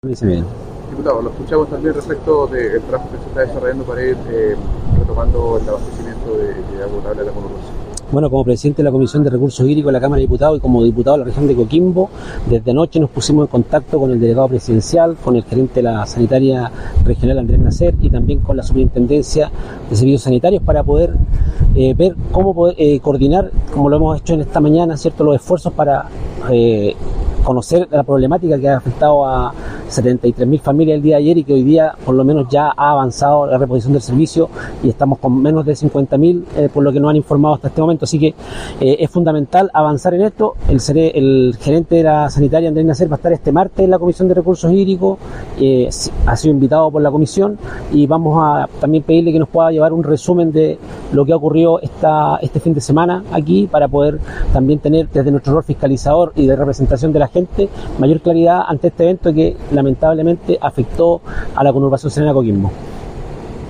Mientras que el Diputado Víctor Pino, en su calidad de Presidente de la Comisión de Recursos Hídricos de la Cámara de Diputados y Diputadas, acotó que citarán a la gerencia de la empresa para que informe sobre el corte de agua
NORMALIZACIN-AGUA-Diputado-Victor-Pino.mp3